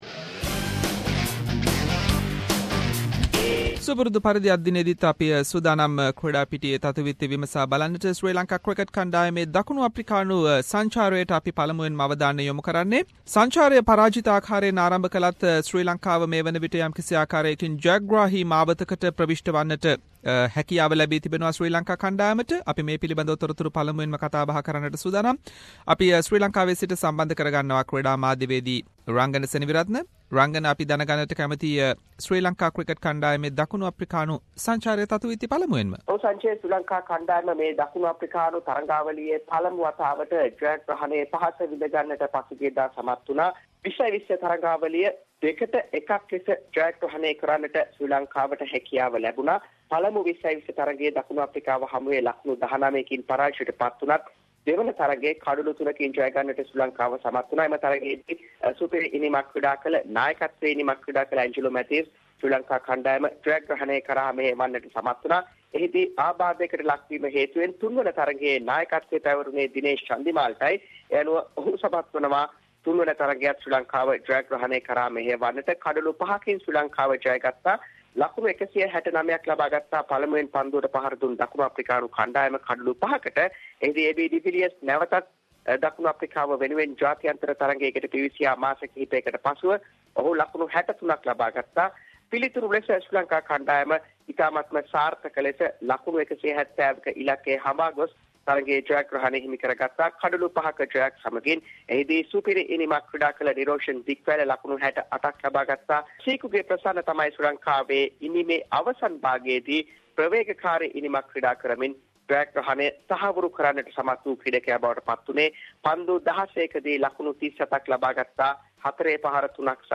In this weeks SBS Sinhalese sports wrap…. Latest from Sri Lanka Cricket tour to South Africa, Latest from Australia Open tennis tournament and many more local and international sports news.